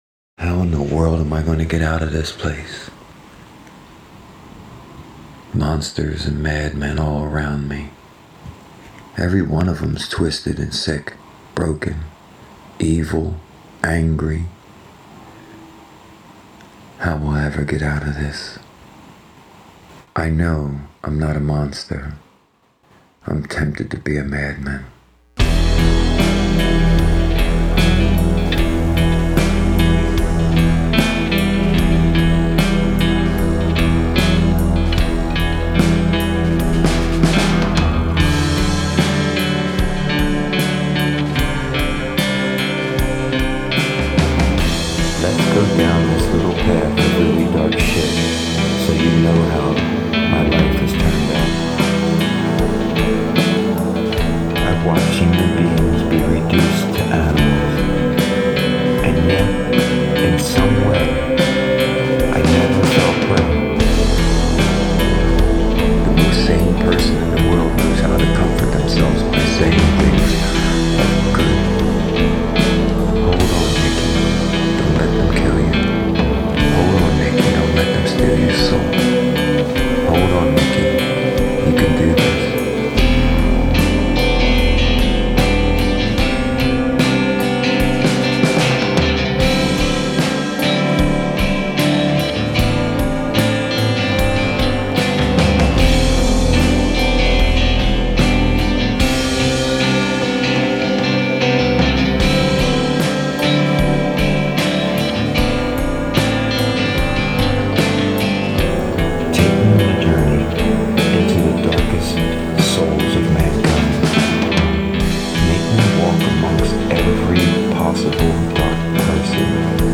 indie rock/darkwave